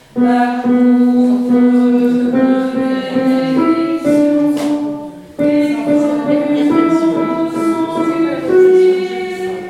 Alto